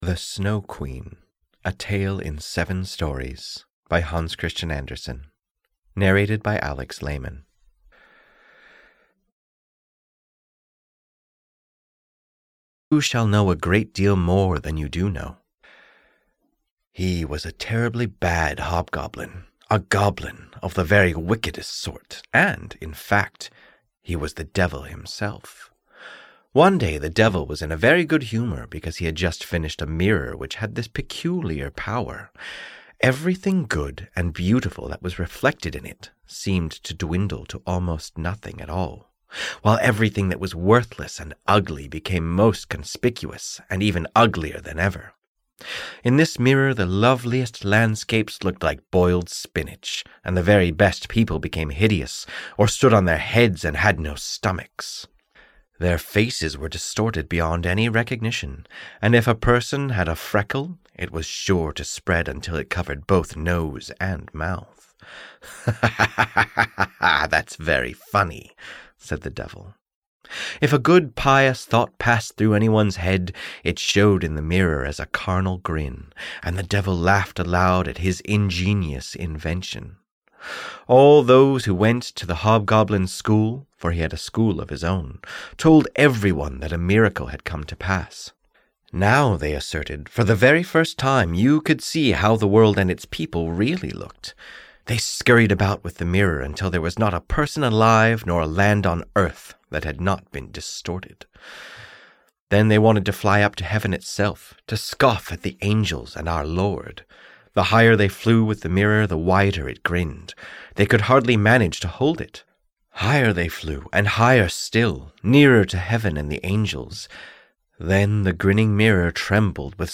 The Snow Queen (EN) audiokniha
Ukázka z knihy